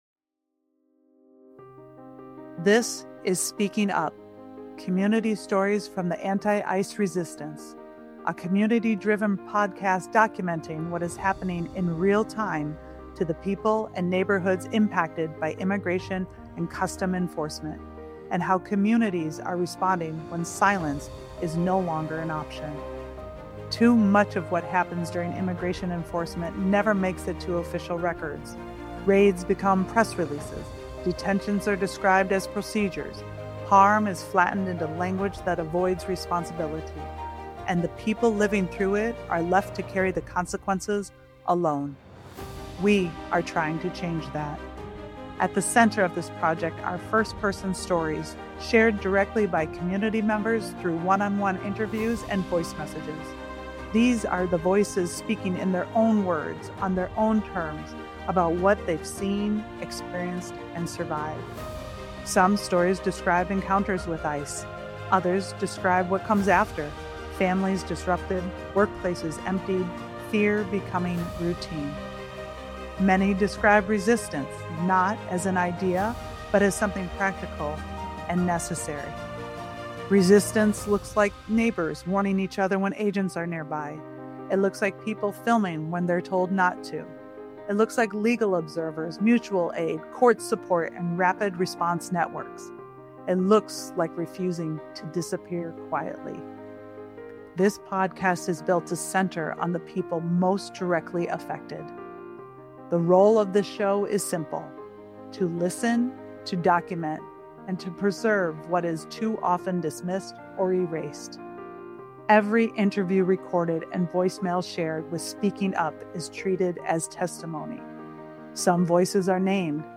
At the center of this project are first-person stories—shared directly by community members through one-on-one interviews and a voice message submission system.